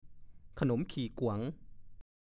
ฐานข้อมูลพจนานุกรมภาษาโคราช